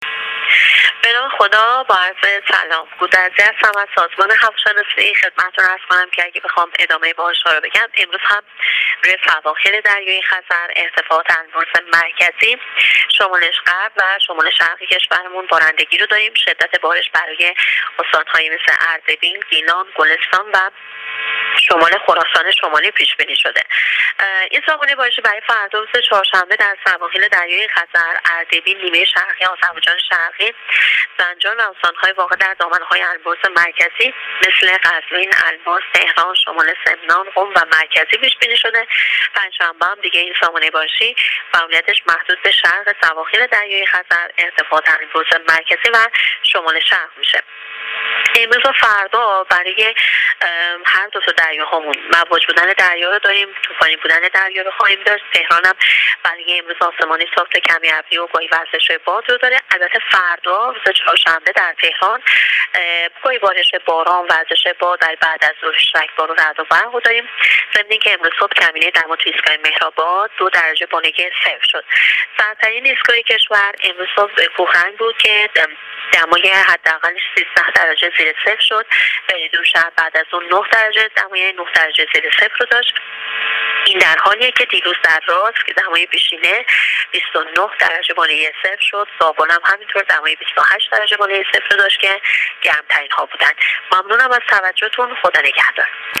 در گفت‌و‌گو با راديو اينترنتی پايگاه خبری آخرين وضعيت هوا را تشریح کرد.